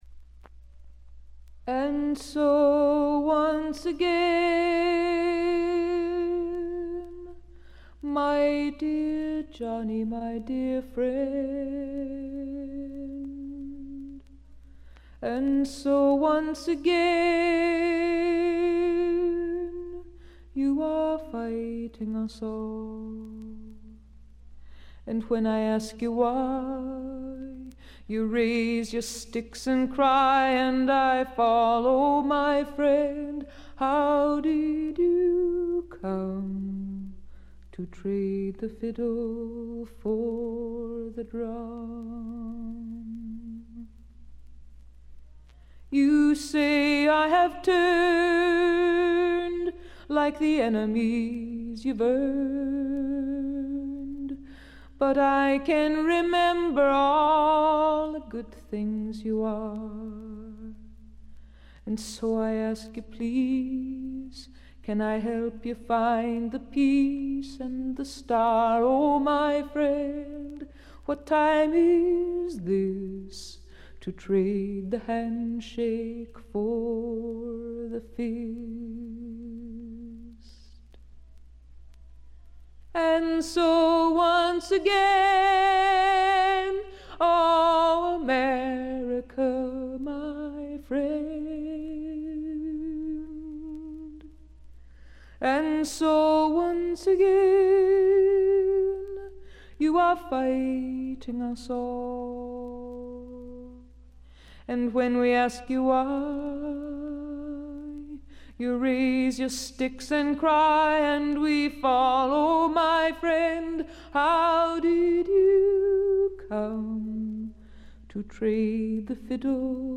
これ以外は微細なバックグラウンドノイズ程度。
透明感のあるみずみずしさが初期の最大の魅力です。
女性フォーク／シンガーソングライター・ファンなら避けては通れない基本盤でもあります。
試聴曲は現品からの取り込み音源です。